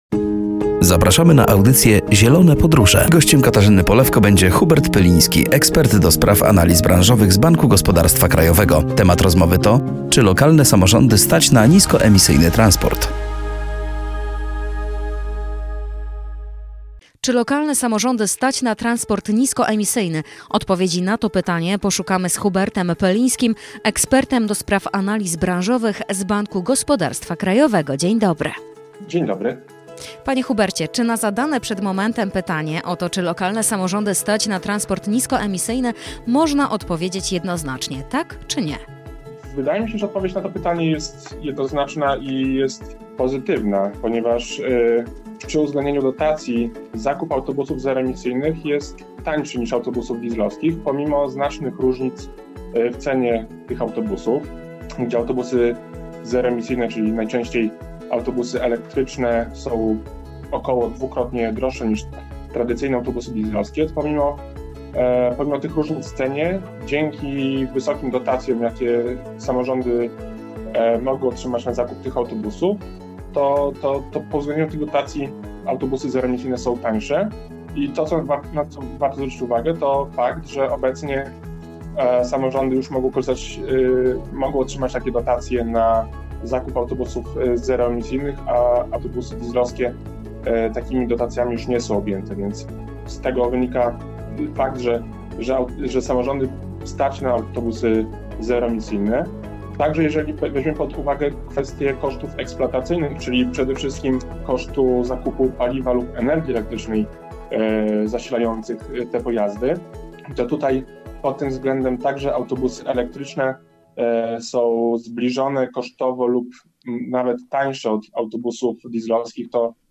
Rozmowa dotyczyła zapytania, czy lokalne samorządy stać na niskoemisyjny transport. Gdzie w Polsce można zauważyć najszybszy rozwój transportu niskoemisyjnego?